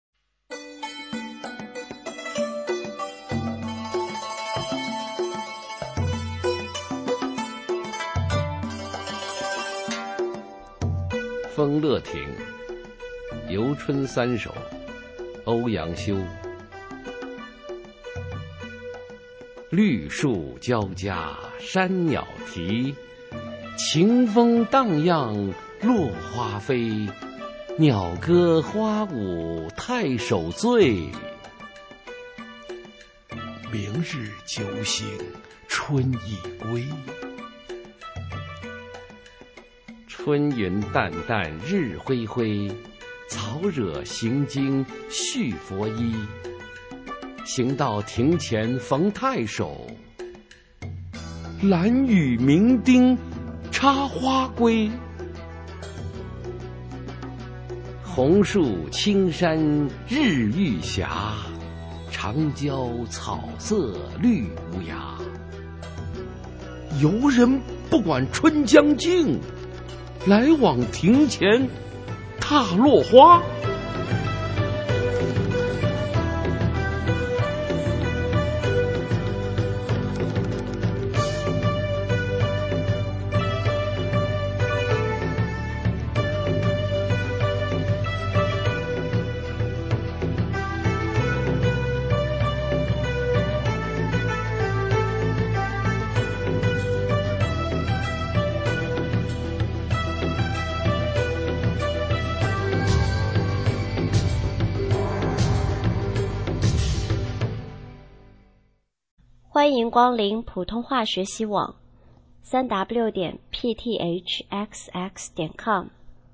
首页 视听 学说普通话 美声欣赏